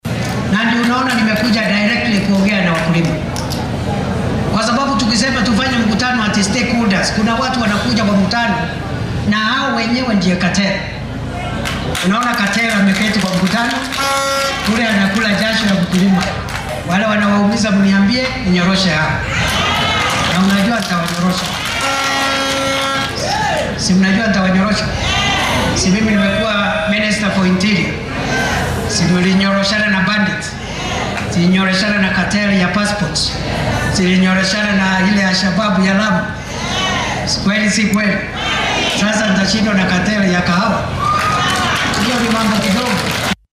Kindiki oo ka hadlaay deegaanka Ndia ee ismaamulka Kirinyaga ayaa ballan qaaday in uu xoojinaayo meel marrinta sharciga kafeega iyo iskaashatooyinka ee sanadka 2025 dhowrka toddobaad ee soo socda, si loo helo maamul wanagsan oo laga helo bulshooyinka qaxwada iyo iskaashatooyinka oo beeralayda lagu dhacay tobanaan sano.